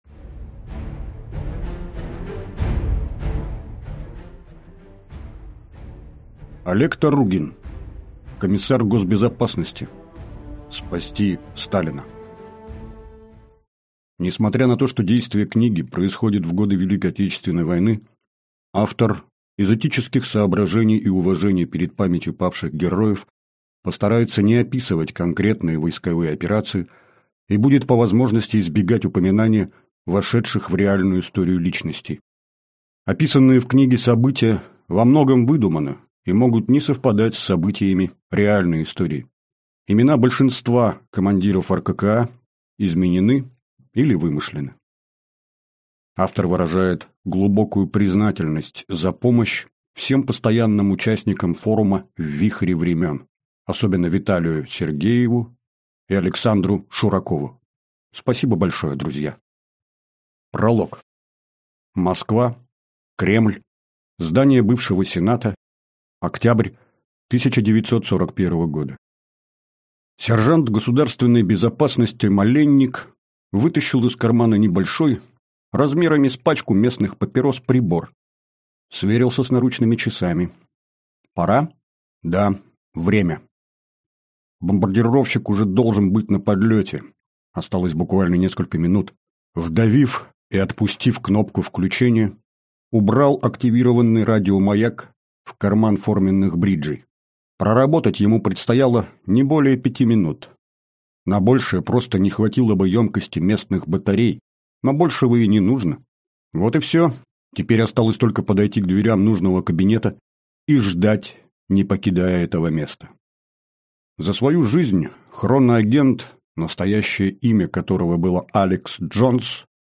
Аудиокнига Комиссар госбезопасности. Спасти Сталина!